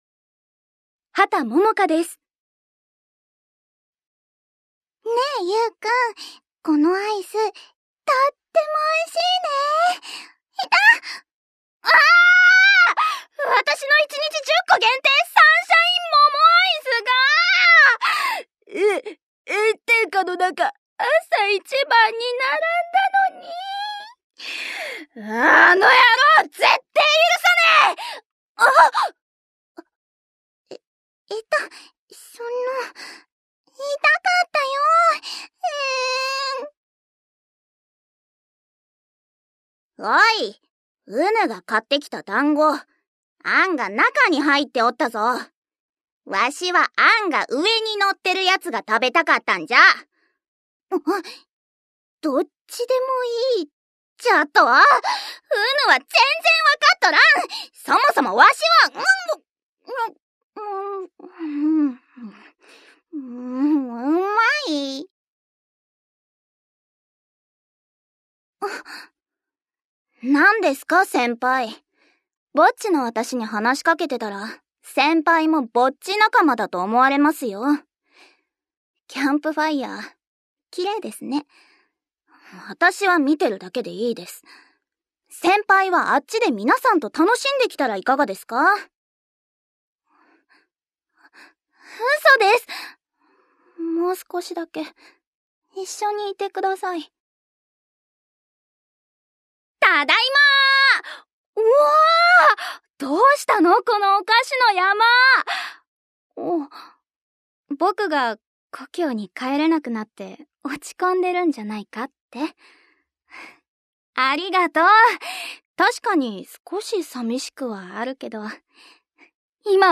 jr. voice actor
サンプルボイス